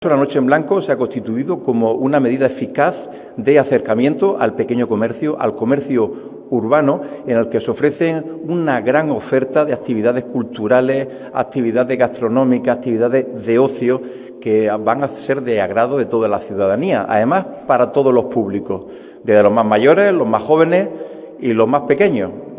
La presentación de este evento se ha llevado a cabo en la Casa Consistorial y la alcaldesa ha estado acompañada por la concejala de Empleo, Comercio, Juventud y Emprendimiento, Lorena Nieto, por el delegado de Empleo de la Junta de Andalucía, Amós García, y  por la diputada provincial de Igualdad y Familia, María Luisa Cruz.